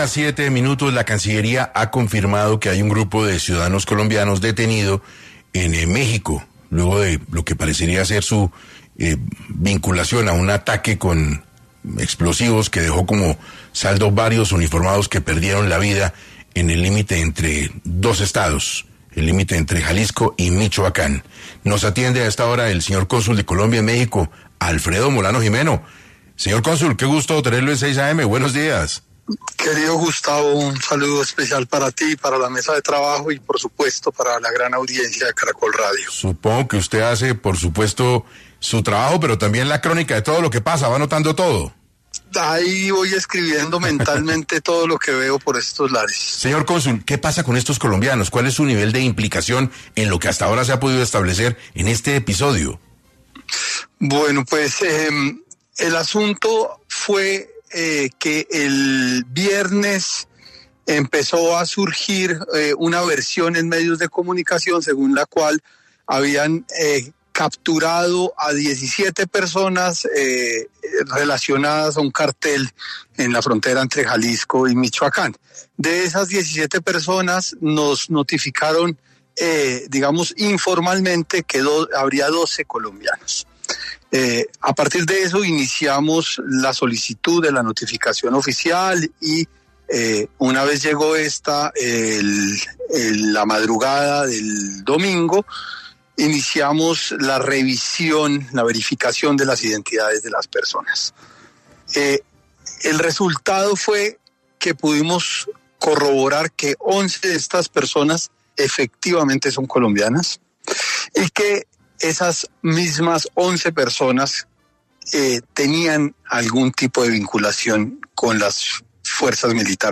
Alfredo Molano Jimeno, cónsul de Colombia en México, explicó para 6AM cuál es la situación del grupo de exmilitares colombianos detenidos en México por presuntamente haber perpetuado un ataque terrorista